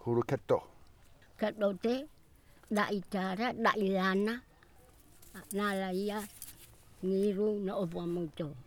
Genre: Medicine/huru.
We were only the two of us, and a cat that kept miaowing. Outdoors condition: I handheld the Zoom and recorded with the internal microphones.
Recording made in kampong Nara, Keli domain.